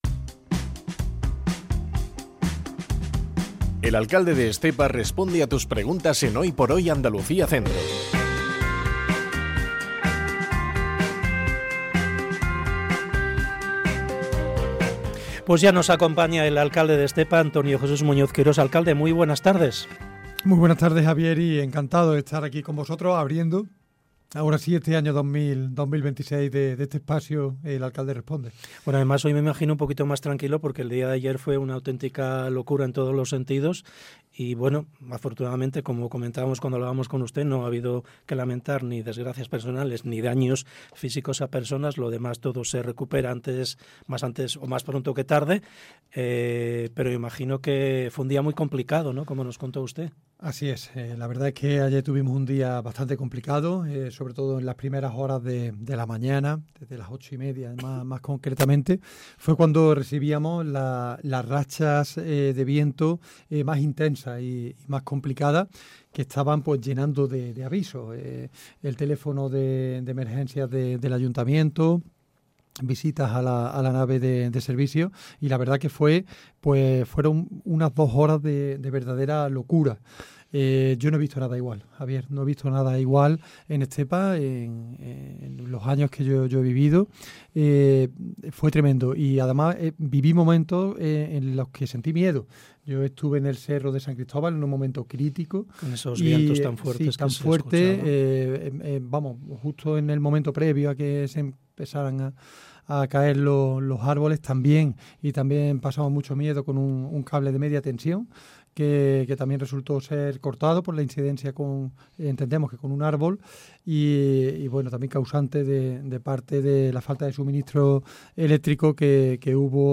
EL ALCALDE RESPONDE 29 ENERO 2026 Antonio Jesús Muñoz, alcalde de Estepa, responde a las preguntas de los oyentes en Hoy por Hoy SER Andalucía Centro